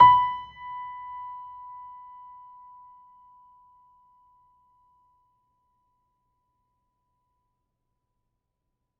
pianoSounds